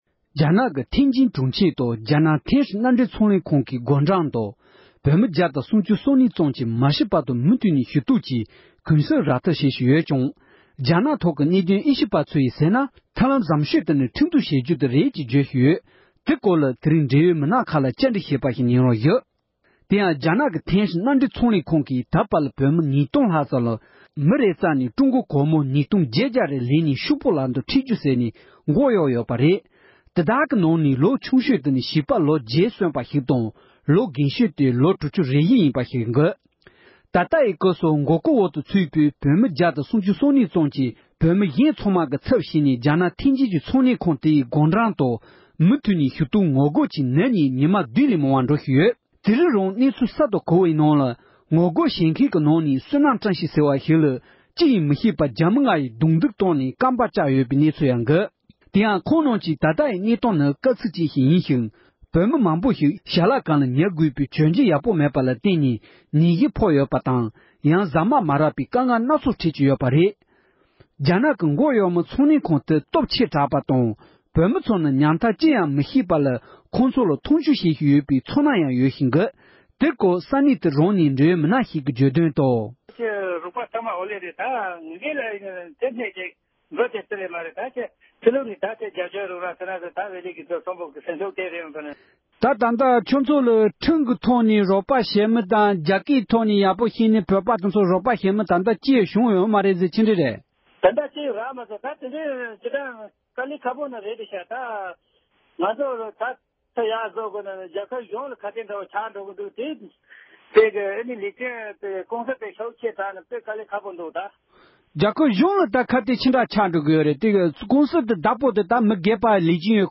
འབྲེལ་ཡོད་མི་སྣ་ཁག་ལ་བཀའ་འདྲི་ཞུས་པ་ཞིག་ལ་གསན་རོགས༎